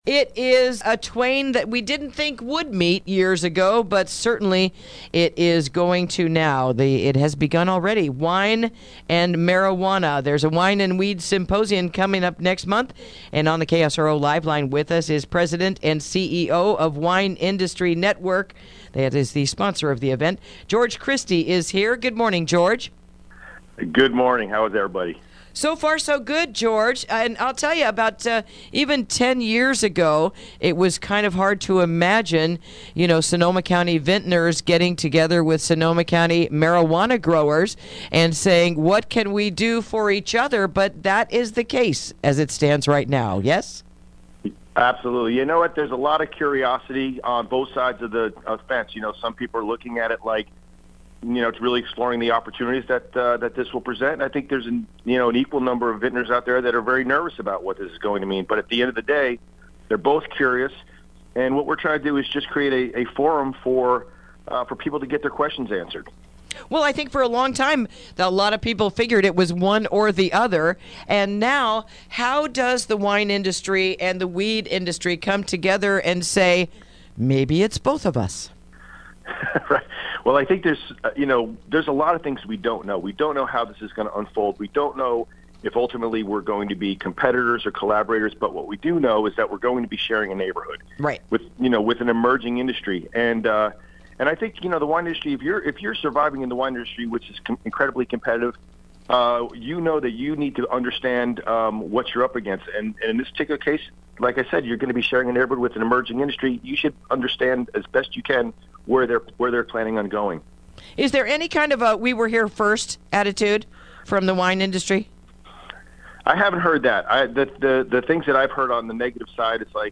Interview: Wine and Weed Symposium Next Month